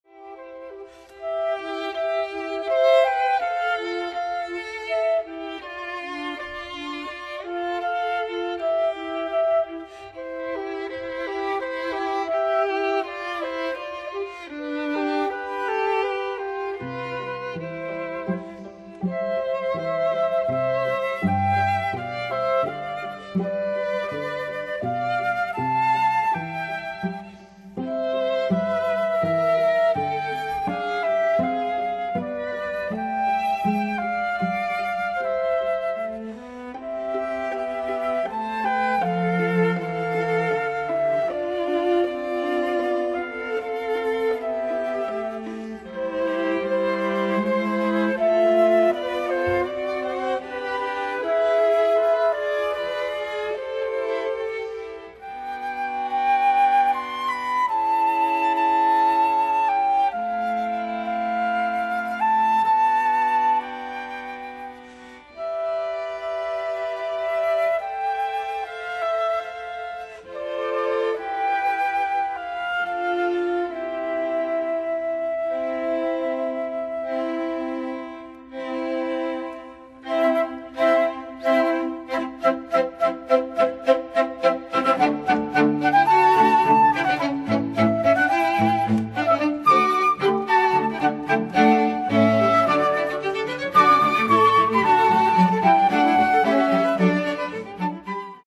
(Flute, Violin, Viola and Cello)
MIDI